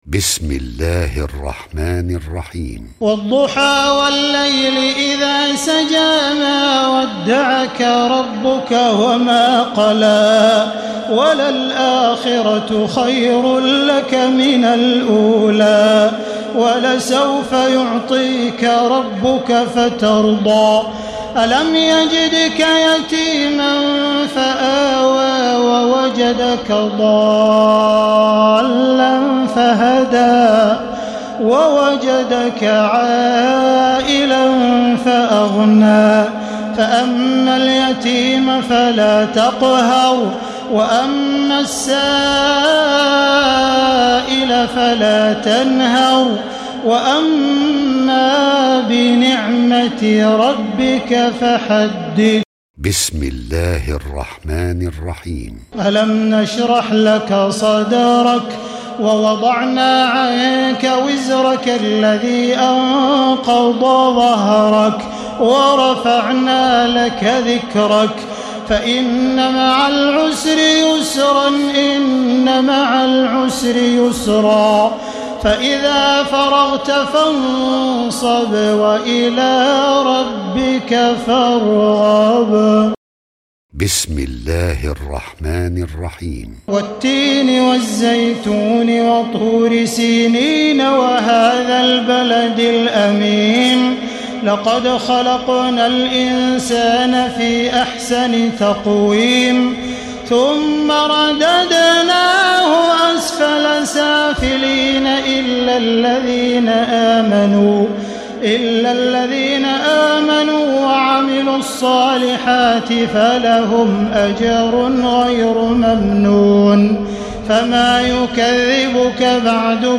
تراويح ليلة 29 رمضان 1436هـ من سورة الضحى الى الناس Taraweeh 29 st night Ramadan 1436H from Surah Ad-Dhuhaa to An-Naas > تراويح الحرم المكي عام 1436 🕋 > التراويح - تلاوات الحرمين